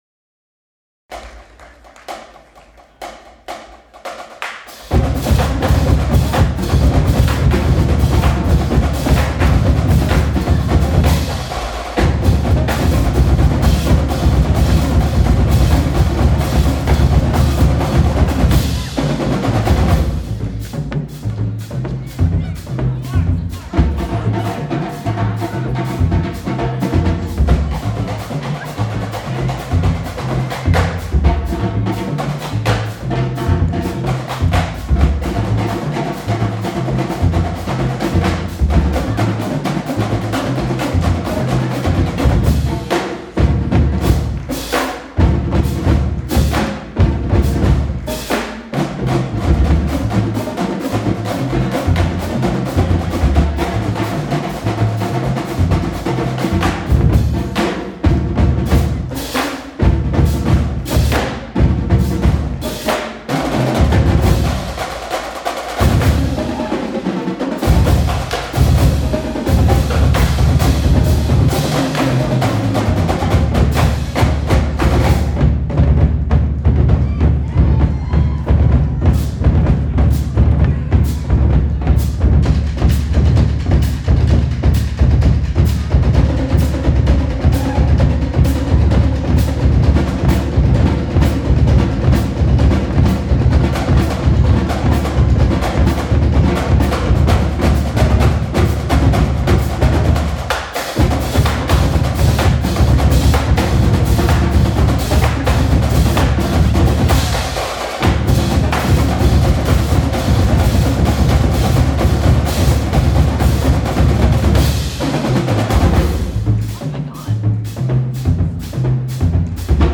2009cadence.mp3